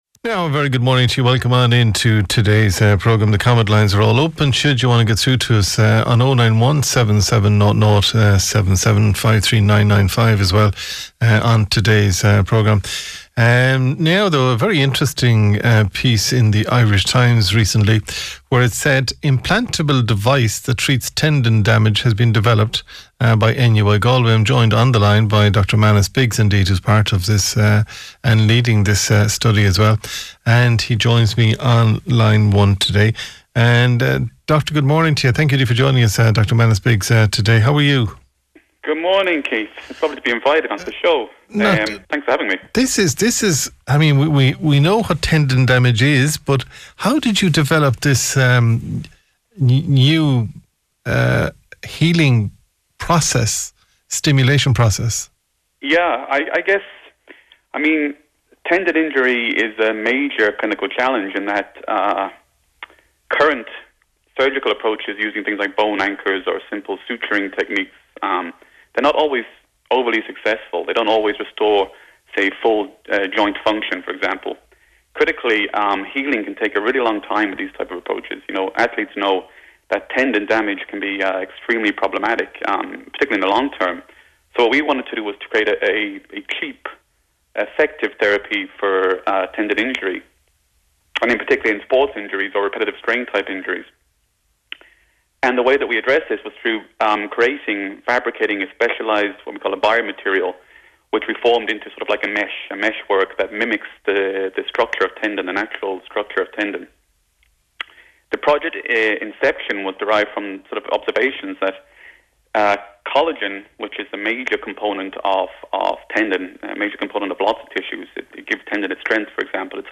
Radio Appearance - Galway Bay FM